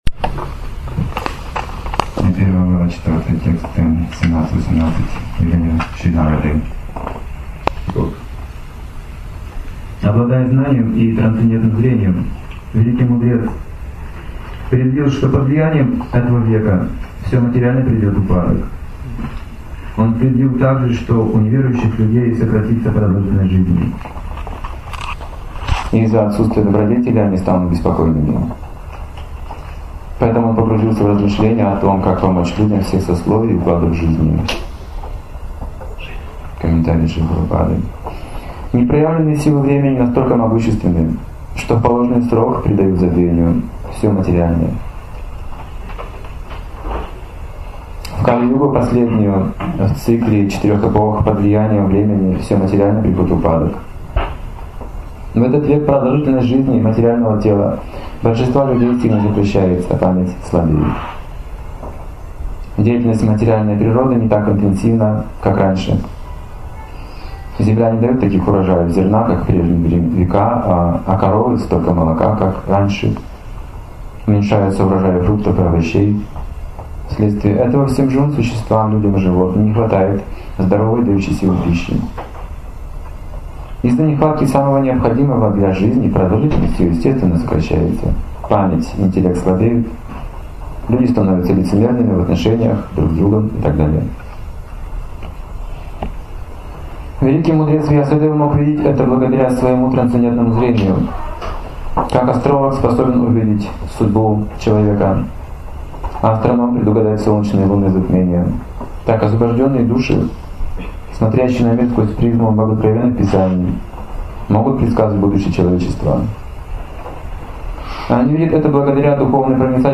Темы, затронутые в лекции: Непроявленные силы Времени Ведическая литература.